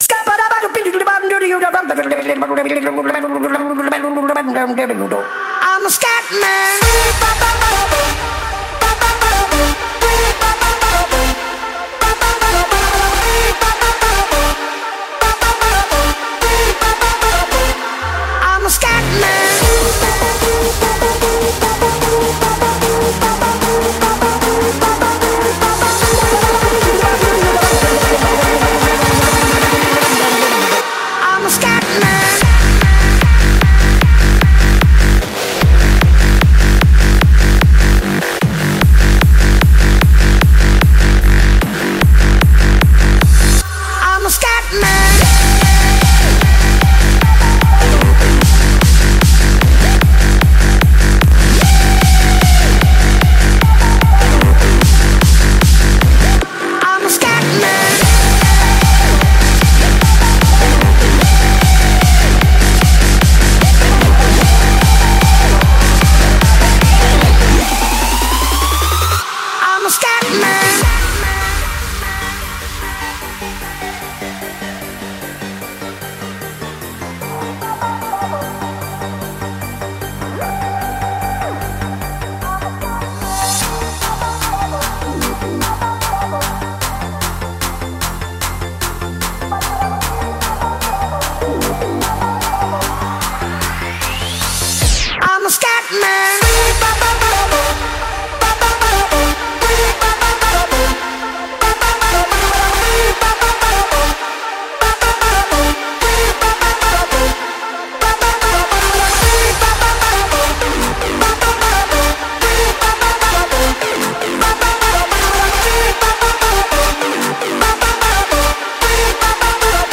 BPM150--1
Audio QualityPerfect (High Quality)